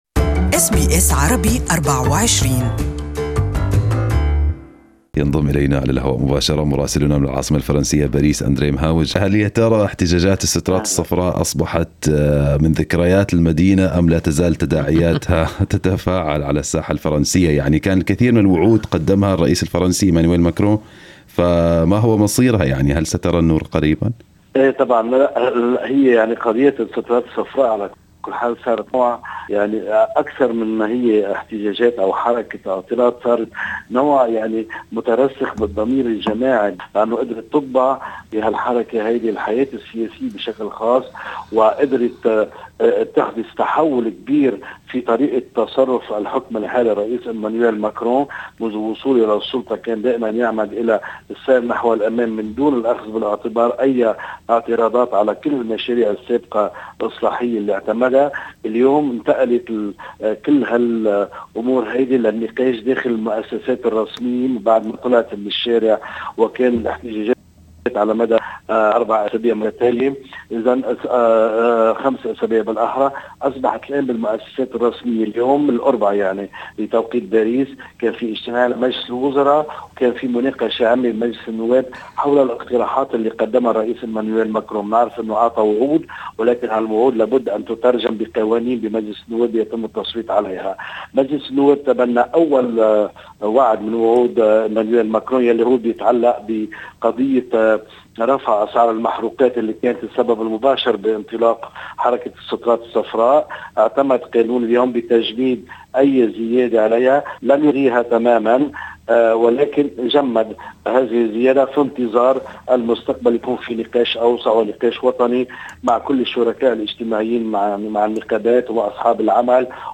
Details with our Paris correspondent.